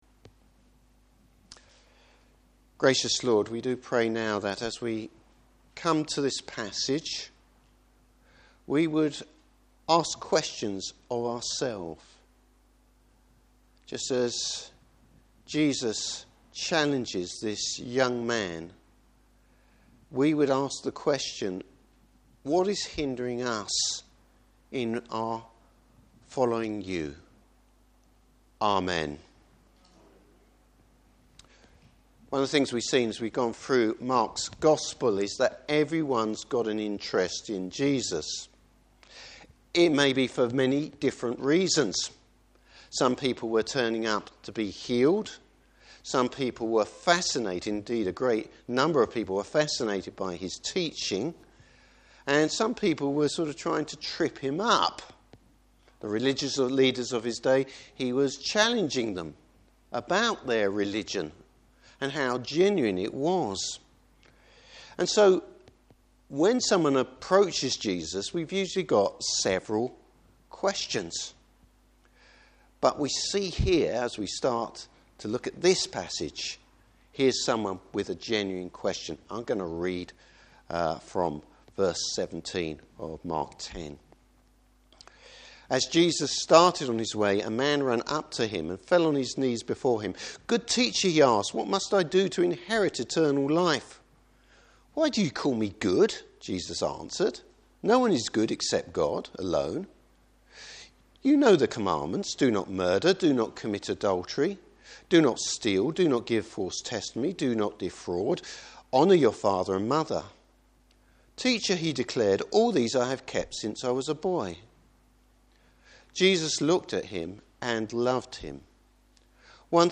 Service Type: Morning Service Jesus reveals a rich young man’s real nature.